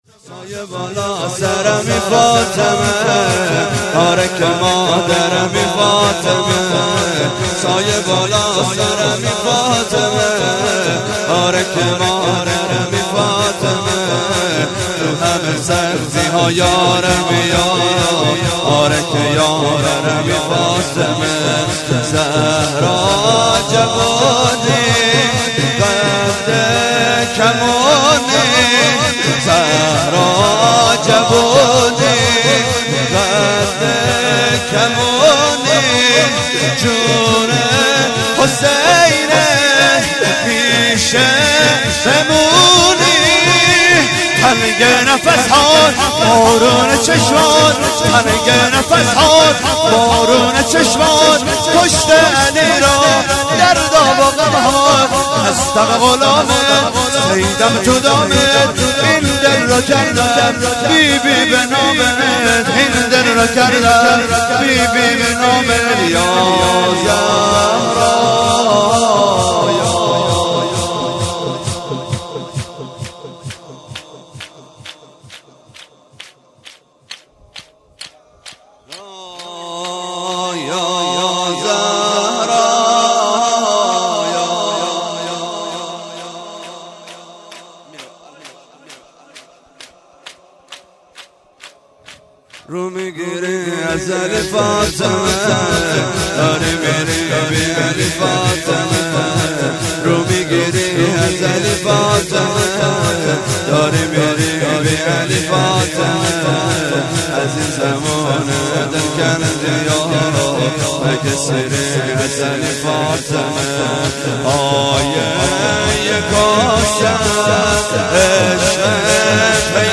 0 0 شور
توسل هفتگی-روضه حضرت زهرا(س)-13 بهمن 1396